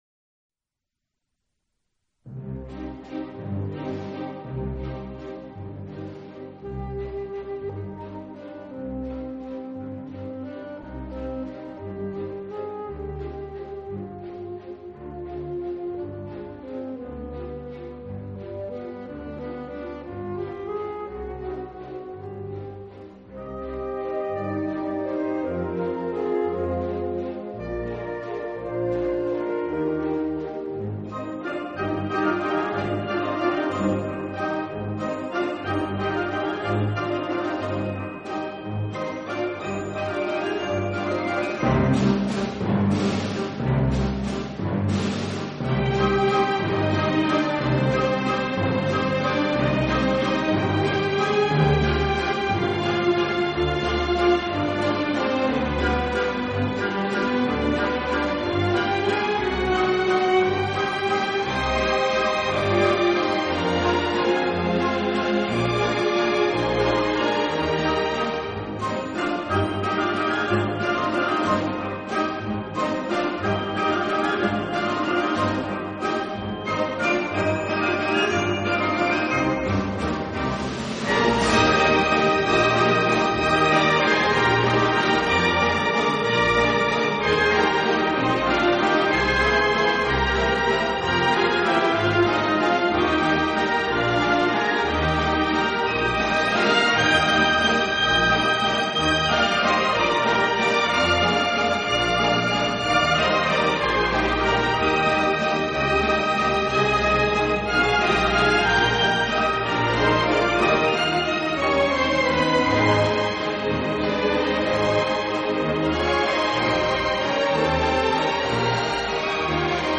每套由100首史上最著名电影交响音乐歌曲组成，6CD名版名演套装以超优